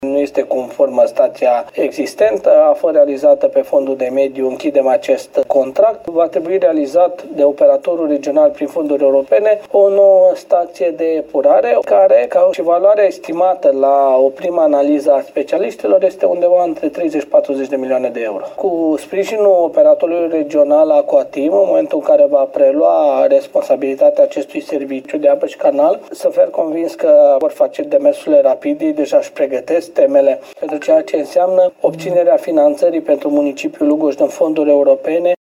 În urmă cu 15 ani, s-au alocat 6 milioane de euro pentru reabilitarea stației de purificare a apei, însă lucrările de modernizare nu au fost executate, spune primarul Lugojului, Călin Dobra.